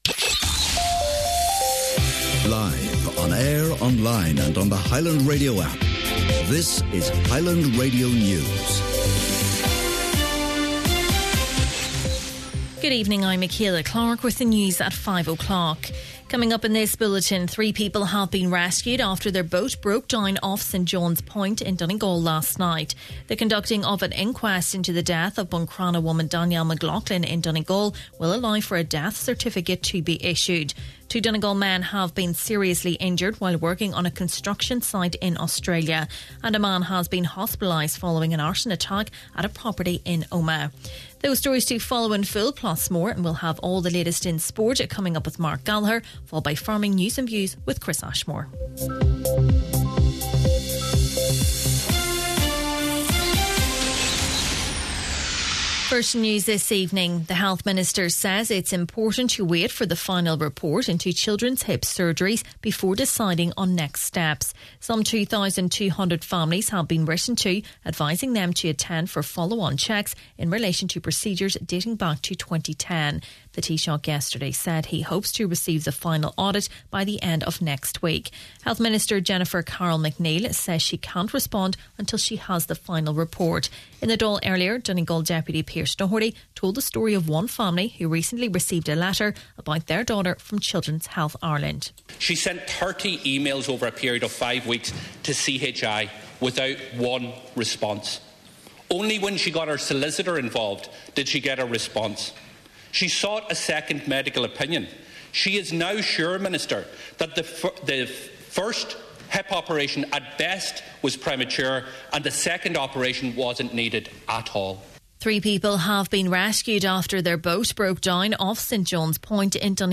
Main Evening News, Sport, Farming News and Obituaries – Thursday, May 15th